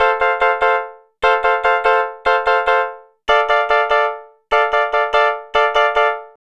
Twisting 2Nite 5 Piano-F.wav